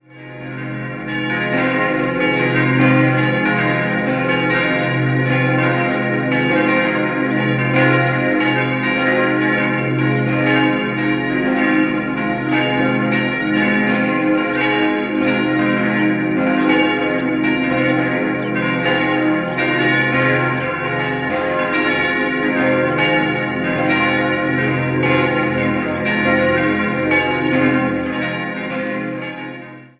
5-stimmiges Geläut: c'-es'-f'-as'-c'' D ie zweitgrößtes Glocke stammt noch aus dem Jahr 1558 und gehört damit der Epoche der Renaissance an. Alle anderen goss im Jahr 1954 die Firma Kuhn-Wolfart in Lauingen.